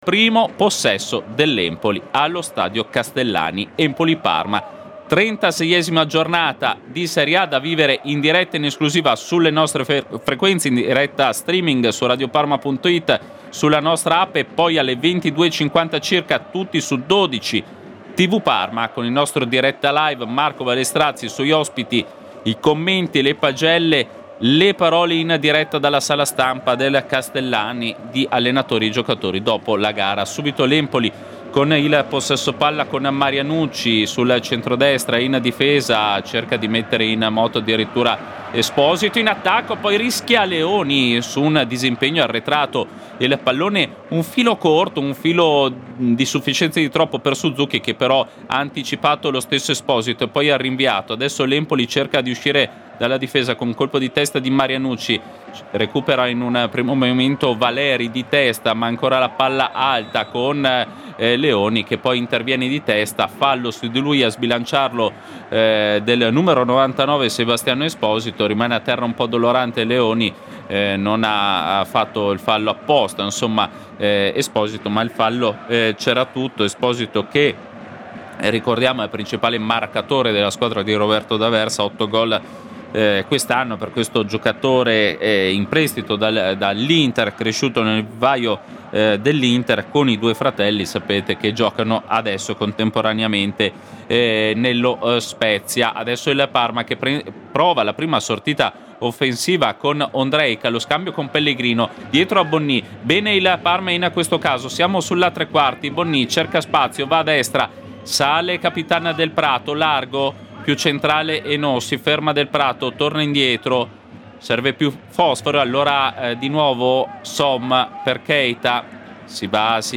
Radiocronache Parma Calcio Empoli - Parma 1° tempo - 10 maggio 2025 May 10 2025 | 00:48:09 1x 00:00 / 00:48:09 Subscribe Share RSS Feed Share Link Embed